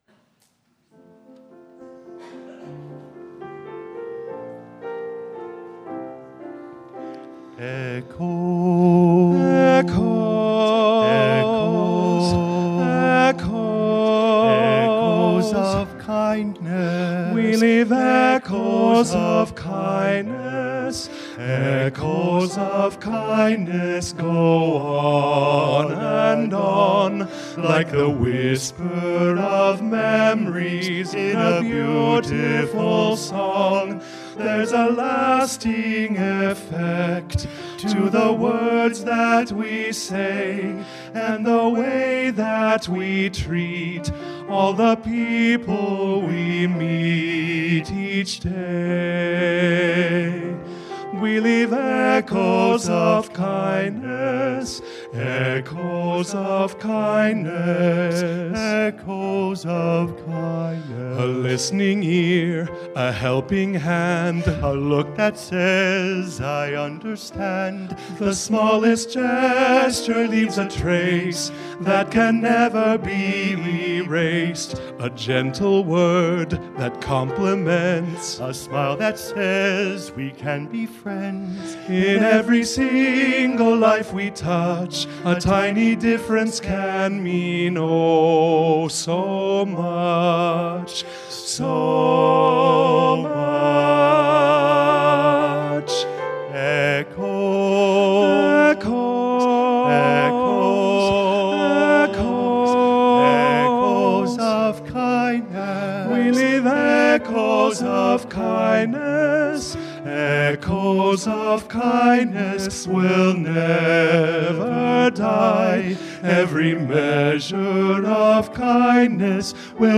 Choir and Instrumental Music